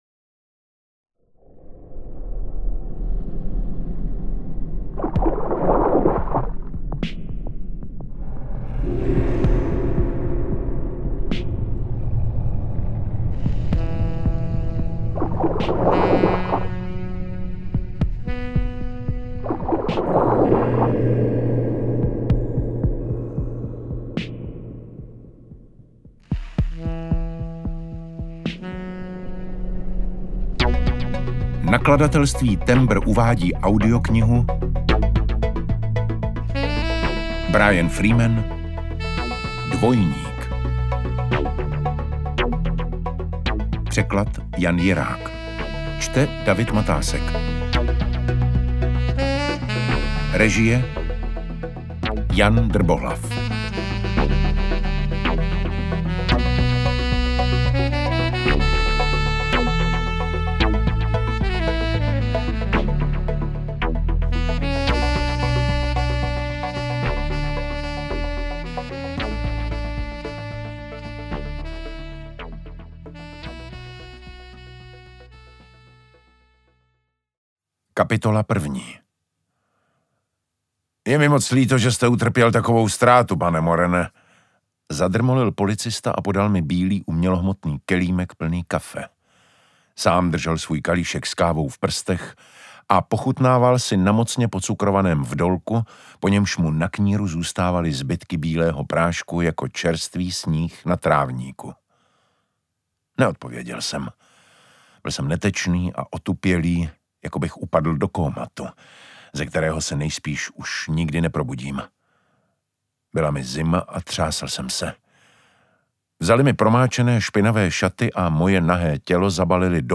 Čte: David Matásek
audiokniha_dvojnik_ukazka.mp3